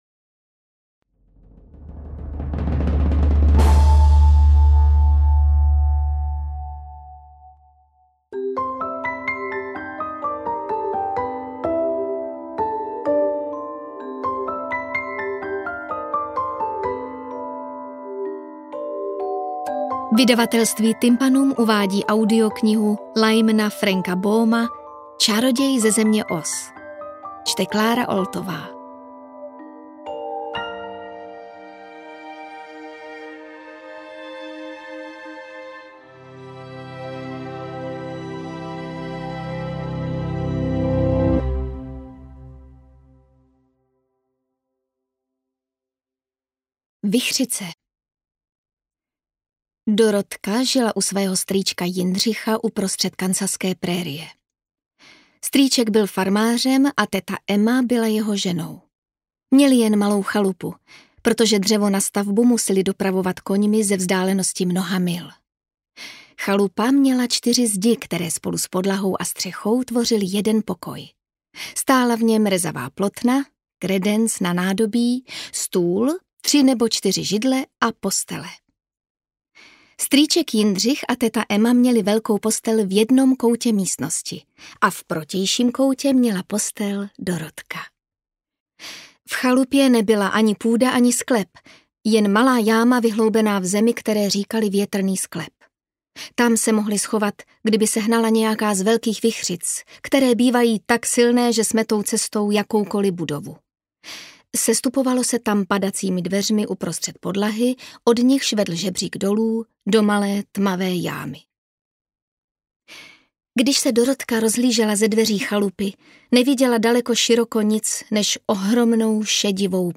AudioKniha ke stažení, 23 x mp3, délka 4 hod. 47 min., velikost 261,3 MB, česky